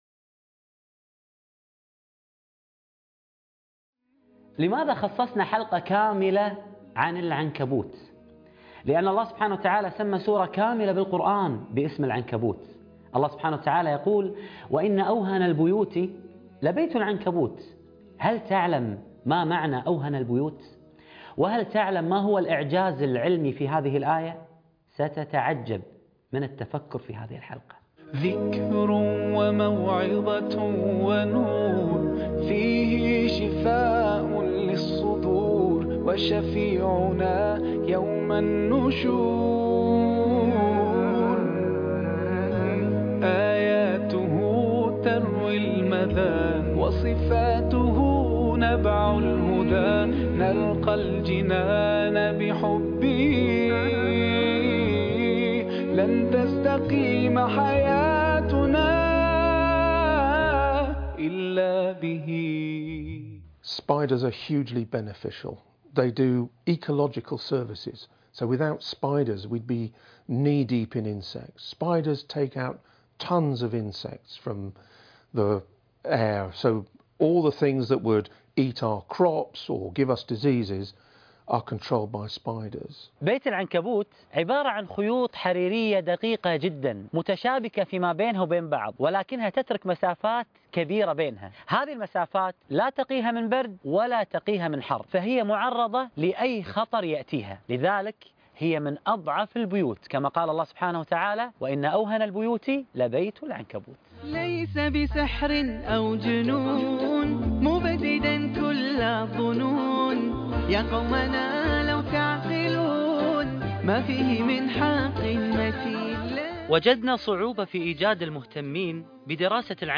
( 9 ) ما هو سر بيت العنكبوت ( فسيروا 2 ) - القاريء